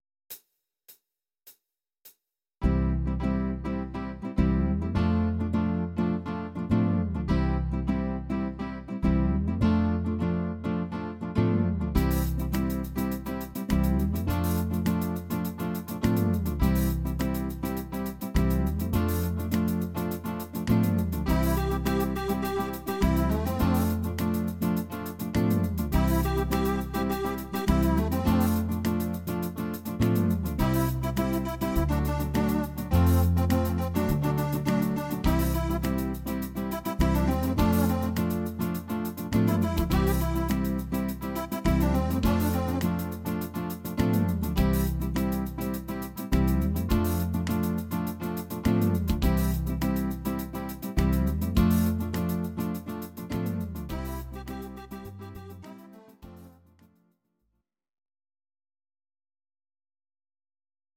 Audio Recordings based on Midi-files
Pop, Rock, 1970s